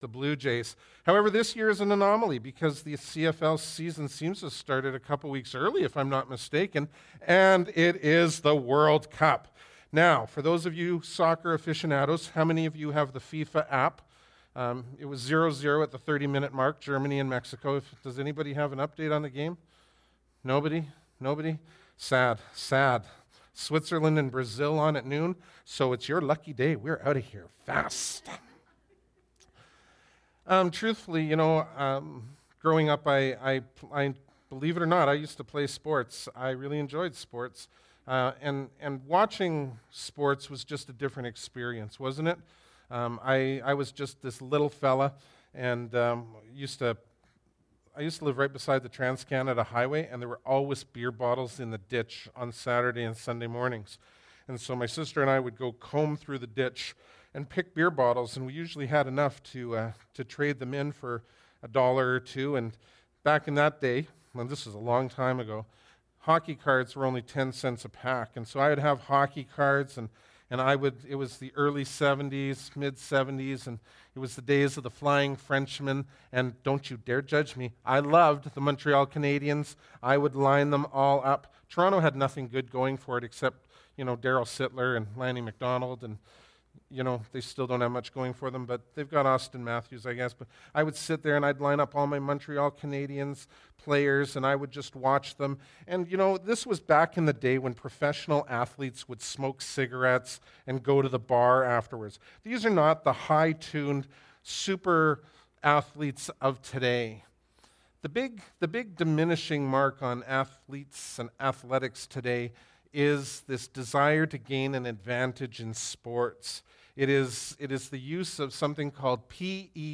Bible Text: Exodus 22:18-23:19 | Preacher